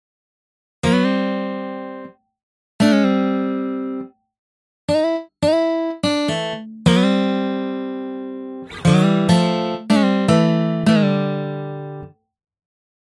After listening to the result, it’s not hard to notice a significant difference between the initial and final audio files.